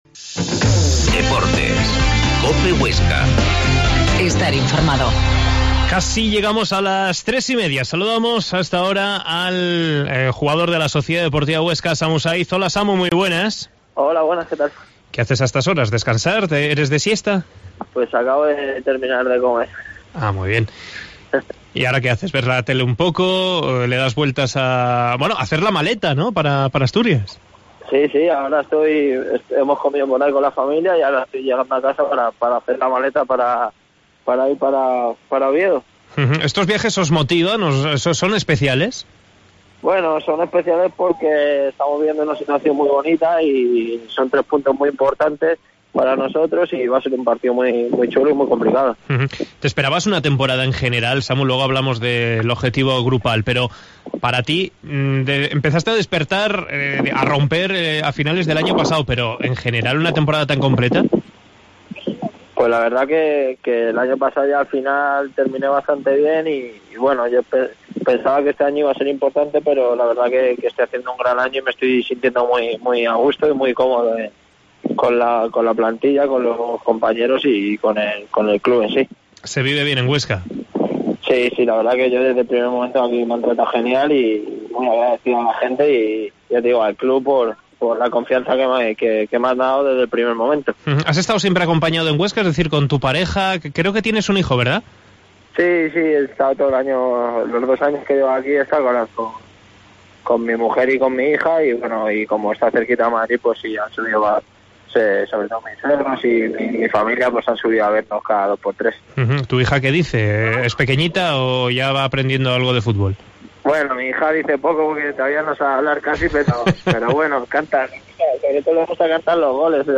Entrevistamos telefónicamente al atacante de la Sociedad Deportiva Huesca para hablar del presente pero también del pasado y el futuro.
Entrevista a Samu Sáiz en COPE Huesca